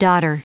Prononciation du mot dodder en anglais (fichier audio)
Prononciation du mot : dodder